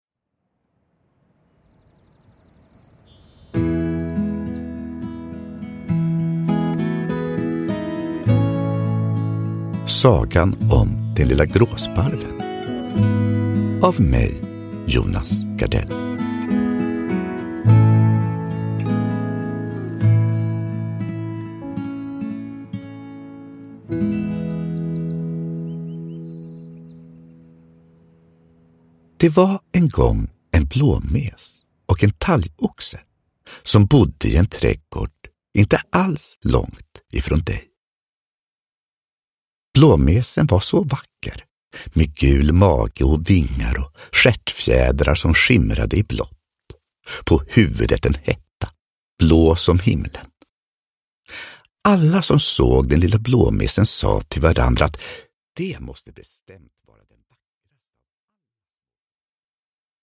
Sagan om den lilla gråsparven – Ljudbok – Laddas ner